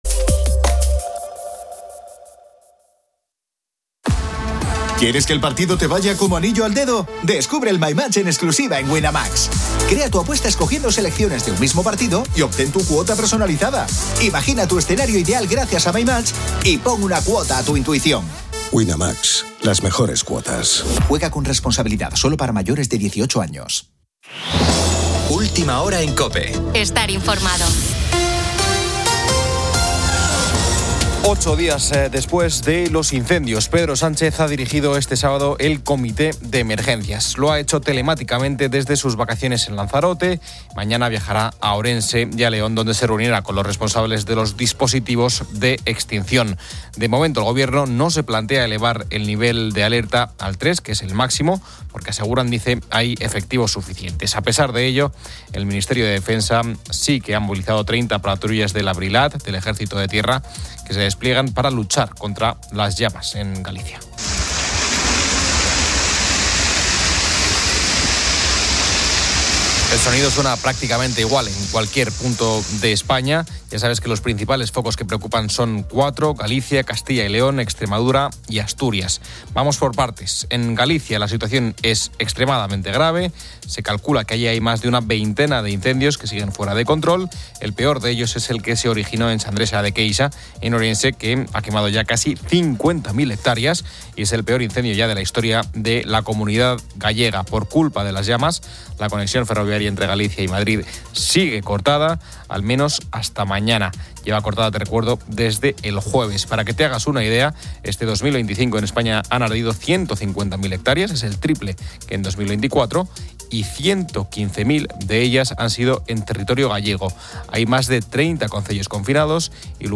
El audio ofrece un resumen de noticias de actualidad y entretenimiento. En el ámbito informativo, se destaca la grave situación de los incendios forestales en España, afectando principalmente a Galicia, Castilla y León, Extremadura y Asturias.